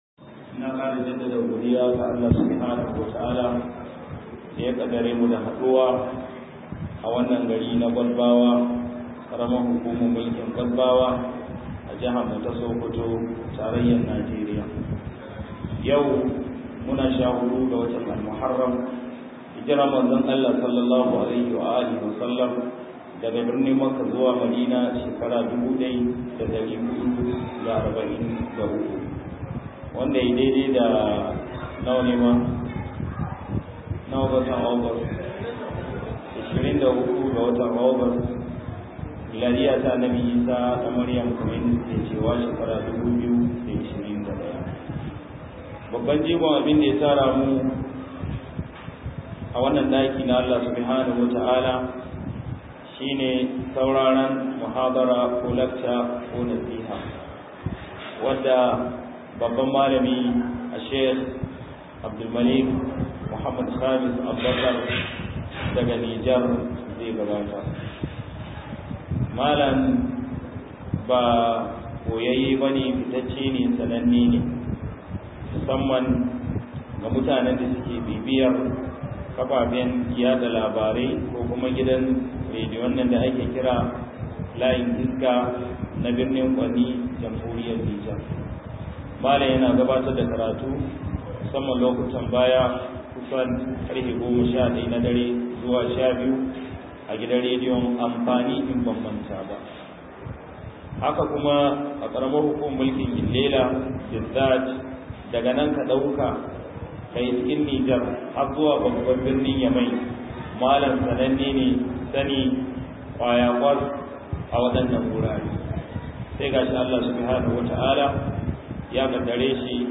178-Kalubalen Kashashen Musulmai - MUHADARA